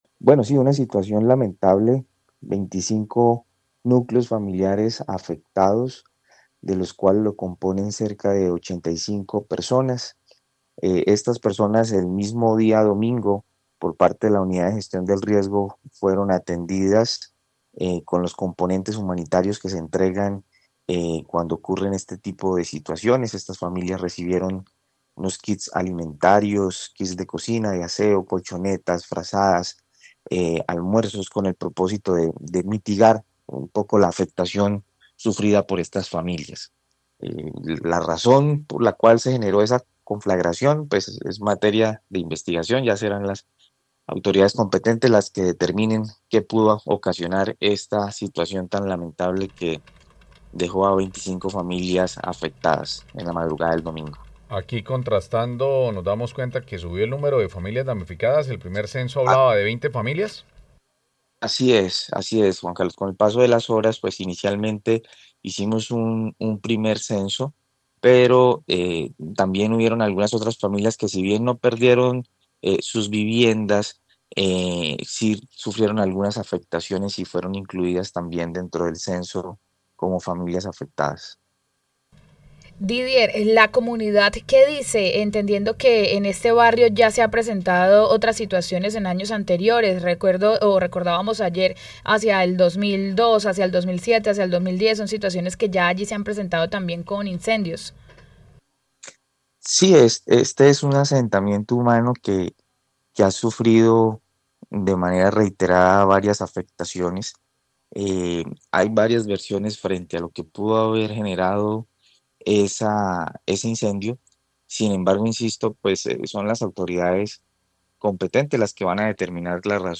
Didier Rodríguez, director de gestión del riesgo de Bucaramanga